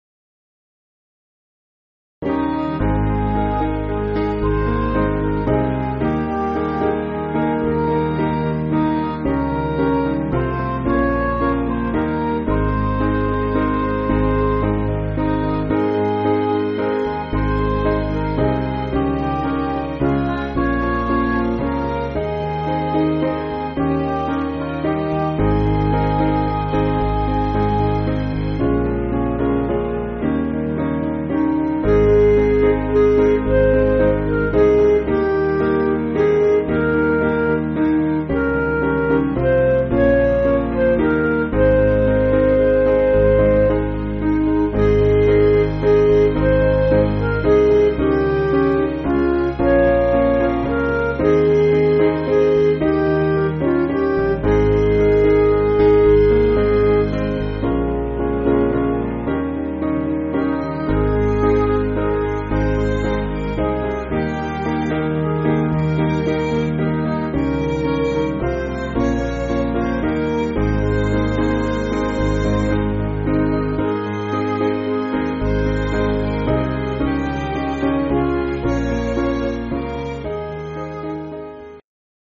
Piano & Instrumental
(CM)   4/Ab